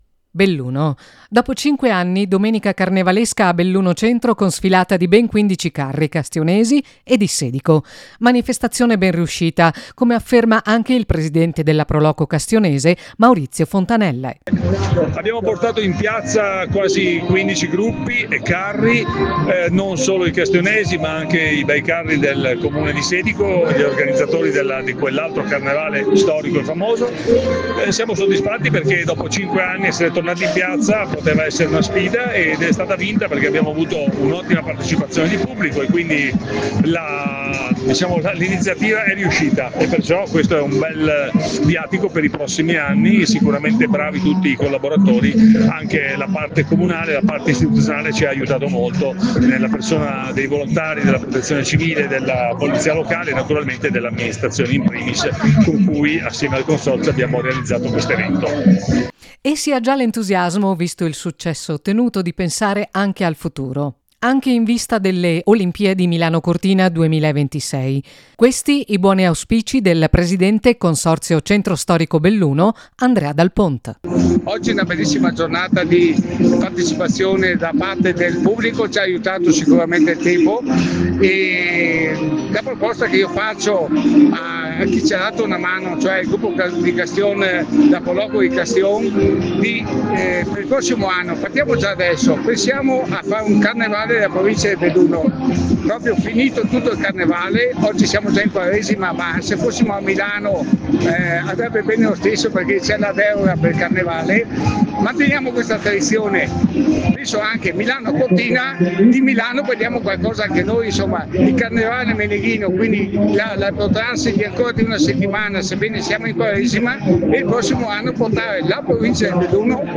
SERVIZIO ED INTERVISTE https